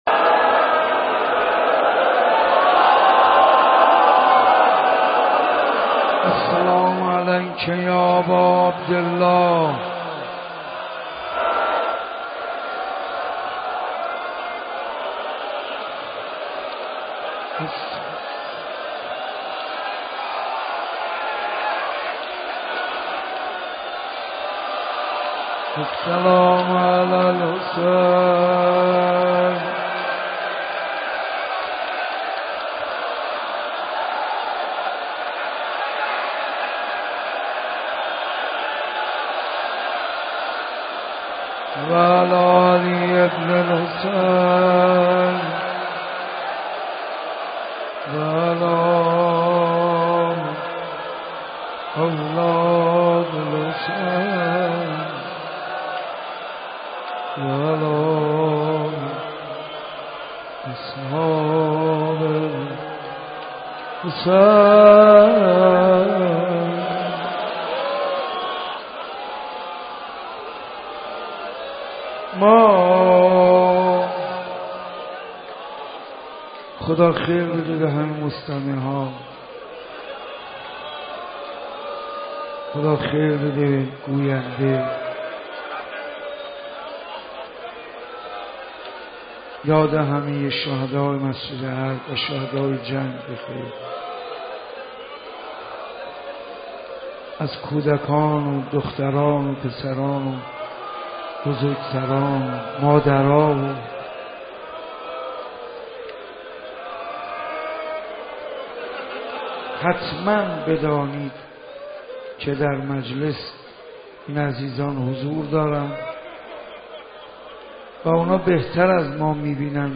حاج منصور ارضی / دهه اول محرم 1391 / مسجد ارک | عصر انتظار .: Asre Entezar :.
مداحی منصور ارضی
مراسم مسجد ارک